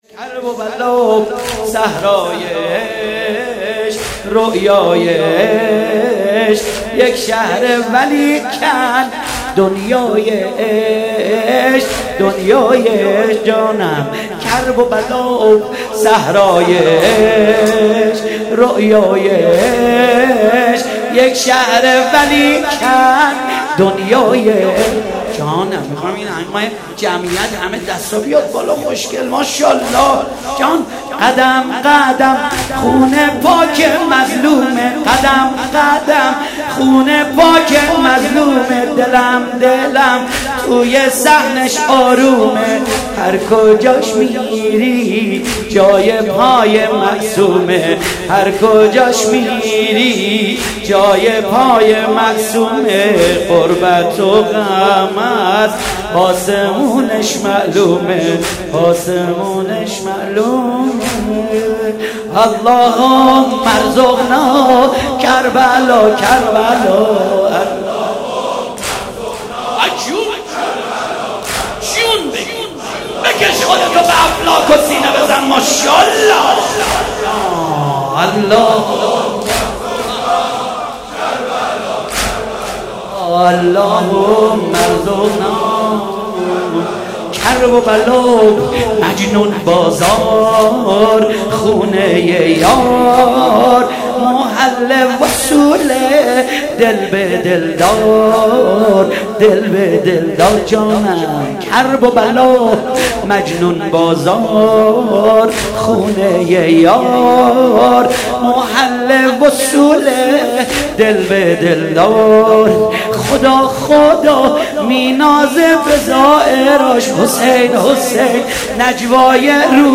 مداح
مناسبت : شب سوم محرم